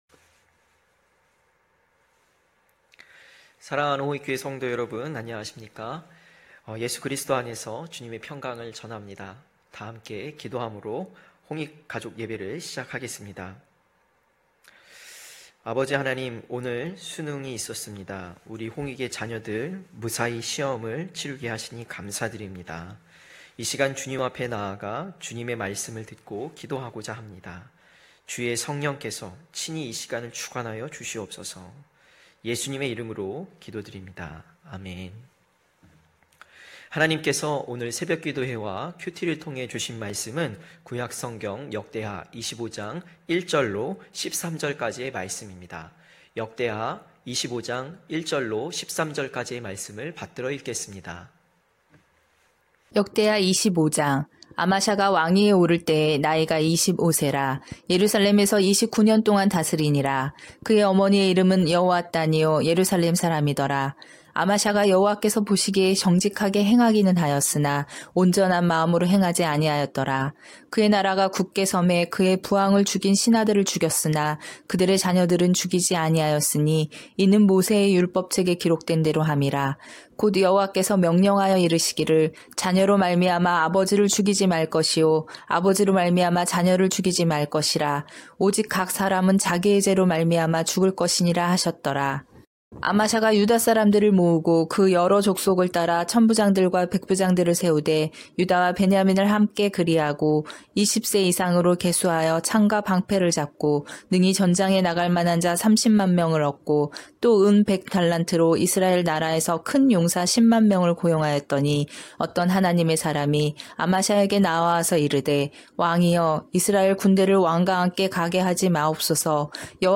9시홍익가족예배(12월3일).mp3